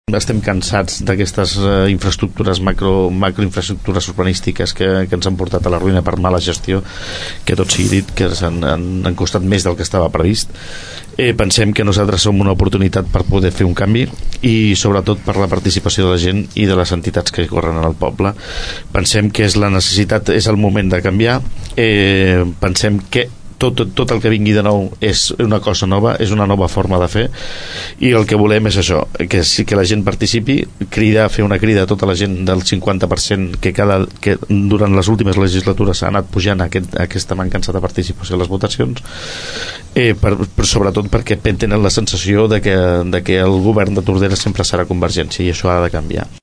debat som
debat-som.mp3